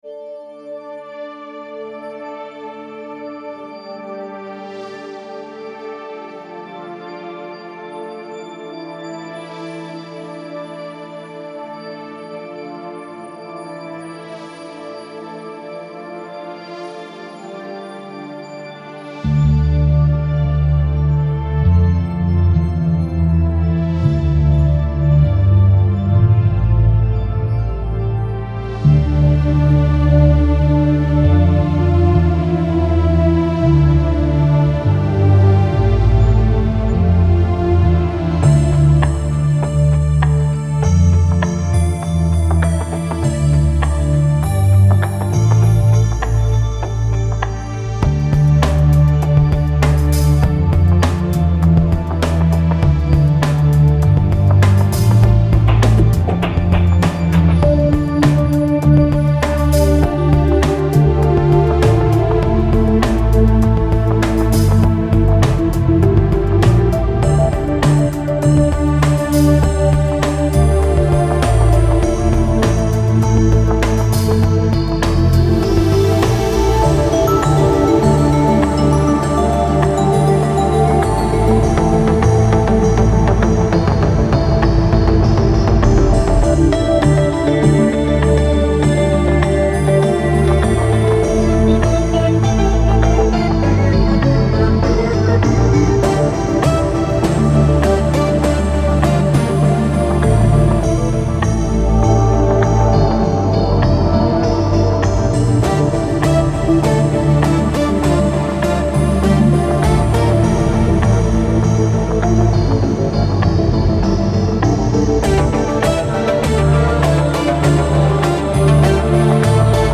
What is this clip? Genre: Psybient.